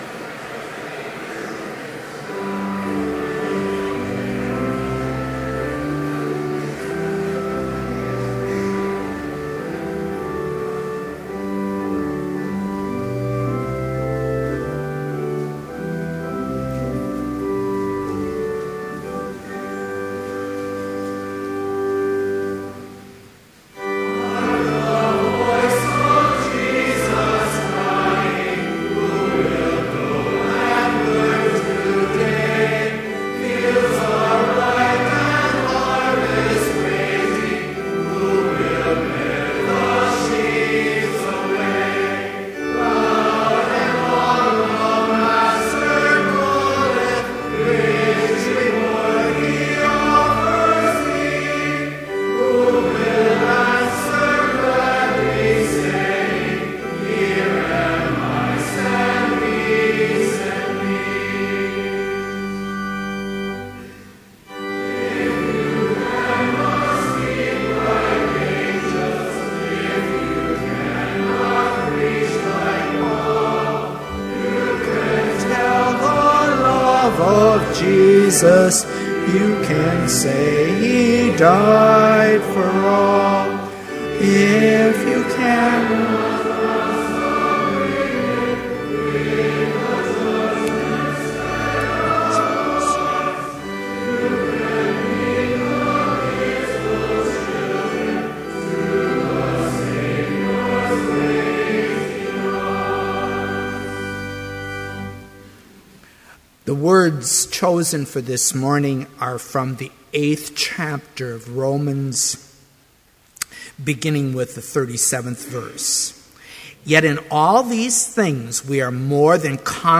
Complete service audio for Chapel - August 30, 2012